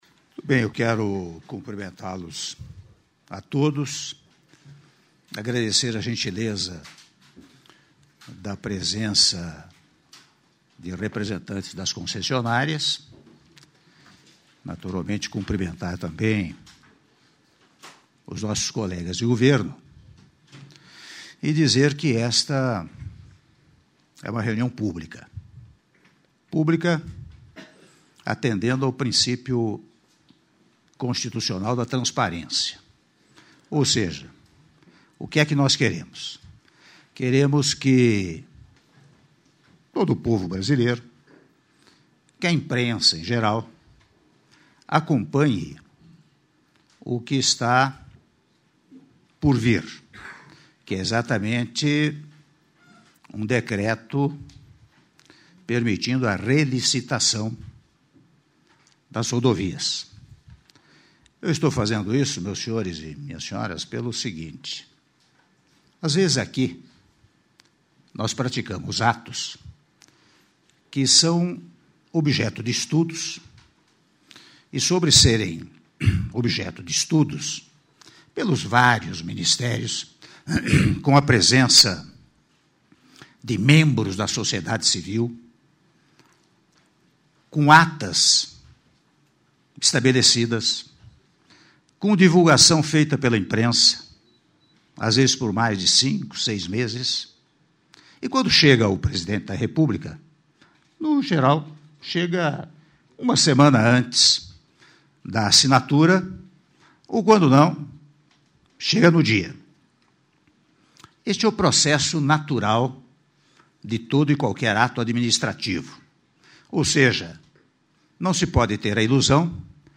Áudio do discurso do presidente da República, Michel Temer, na abertura da reunião Decreto Relicitação-Brasília/DF- (07min12s)